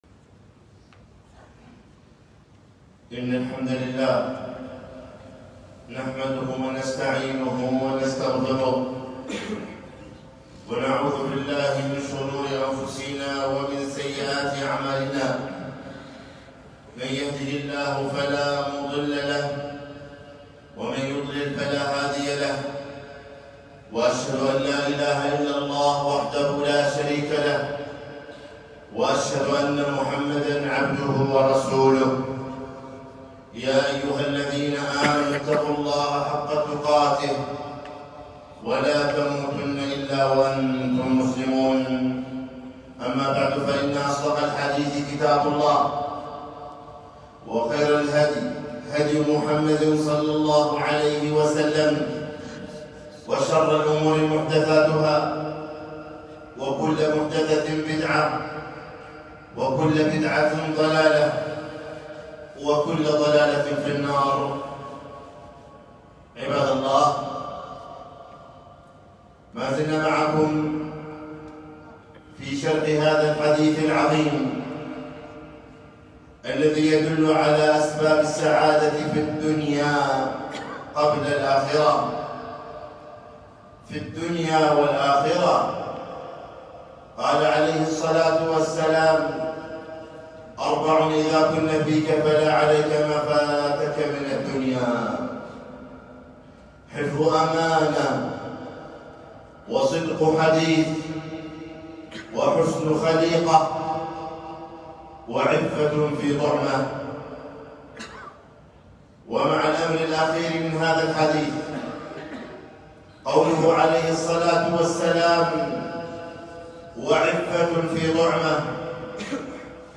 خطبة - العفة عن الحرام في الكسب في الدنيا